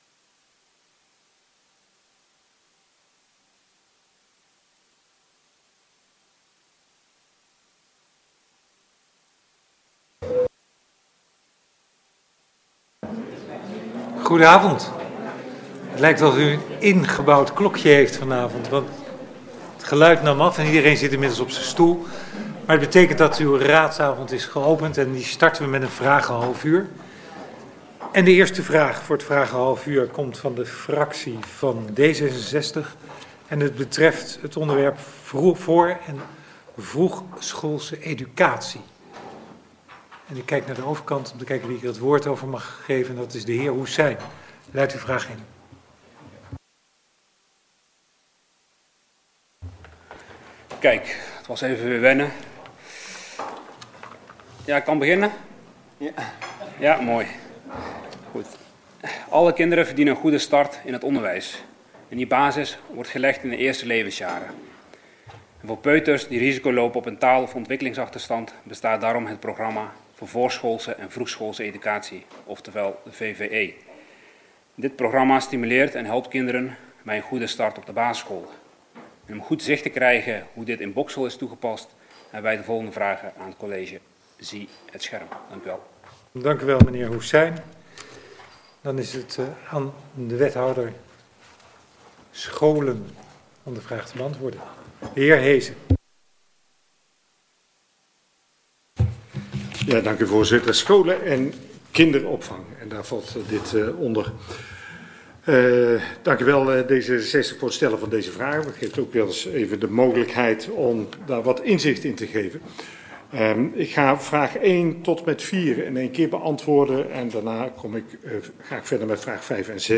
Agenda MijnGemeenteDichtbij - Raadsvergadering Boxtel dinsdag 4 november 2025 19:30 - 23:00 - iBabs Publieksportaal
Locatie Raadzaal Boxtel Voorzitter Ronald van Meygaarden Toelichting Deze vergadering staat in het teken van de begroting 2026.